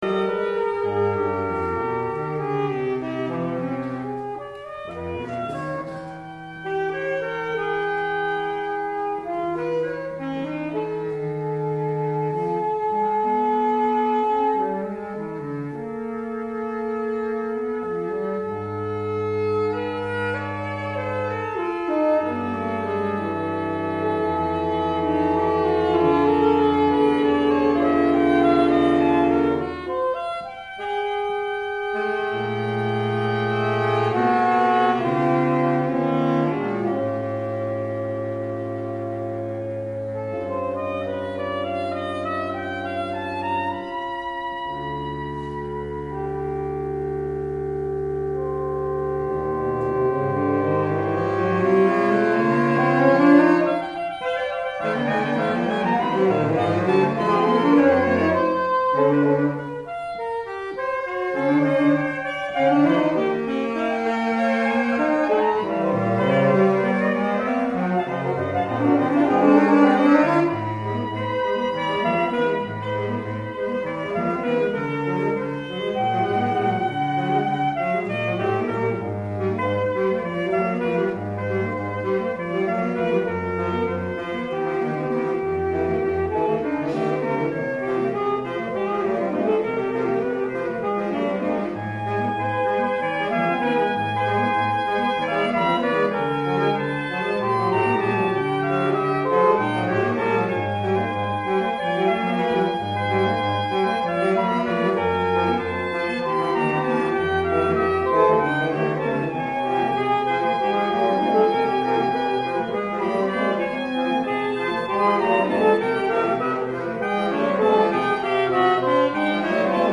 is typical of the more consonant later style